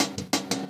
UW_HATZ.wav